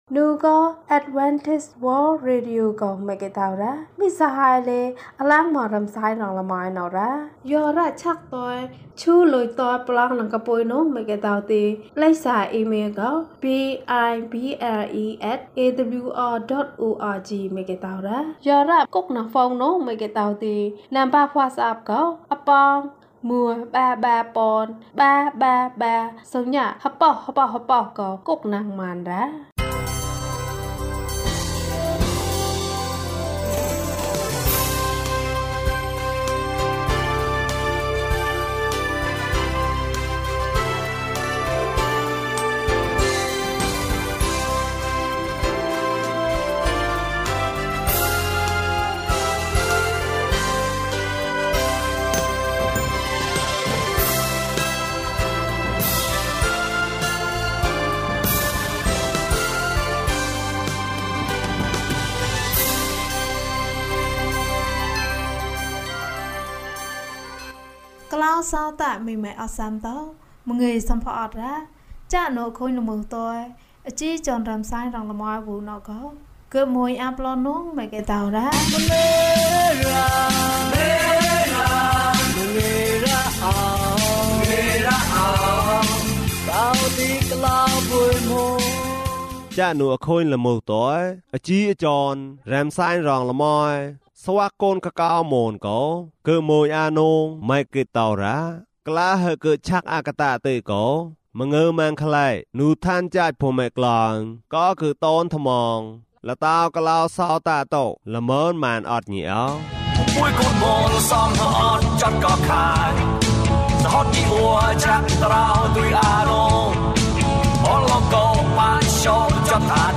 ခရစ်တော်ထံသို့ ခြေလှမ်း။၁၉ ကျန်းမာခြင်းအကြောင်းအရာ။ ဓမ္မသီချင်း။ တရားဒေသနာ။